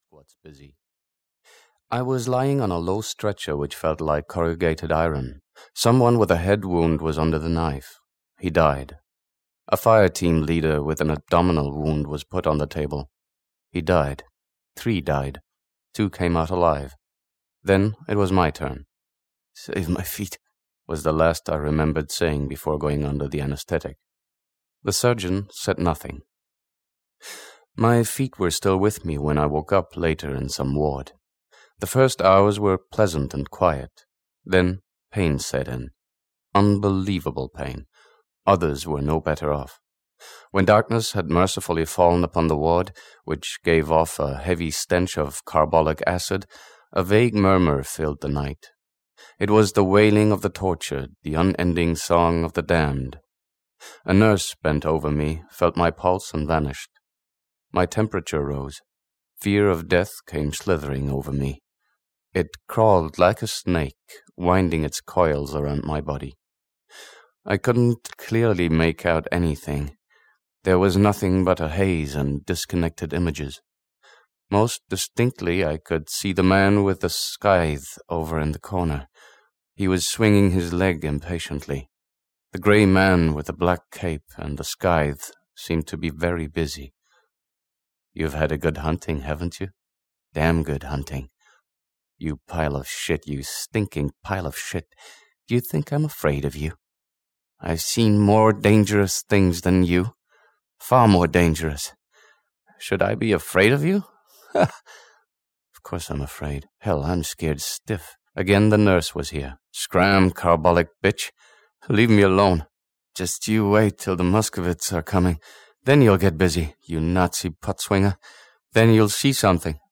Audio knihaComrades of War (EN)
Ukázka z knihy